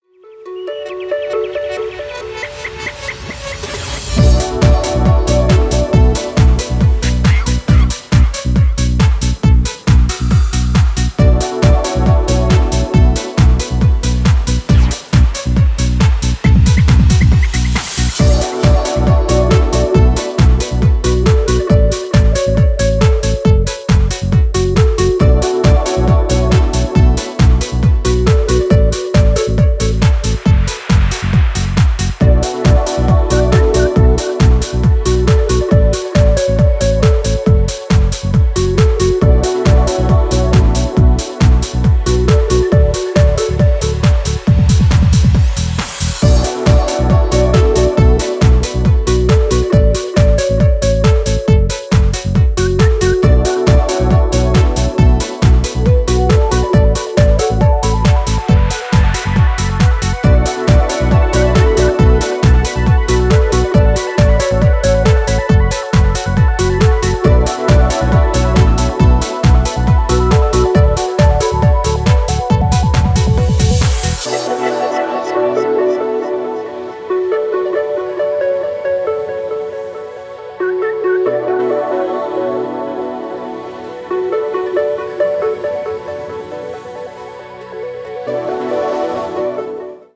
太くバウンシーなキック、メランコリックに揺れる重層的なパッドでビルドアップするメロウ・ミニマル・トランス137BPM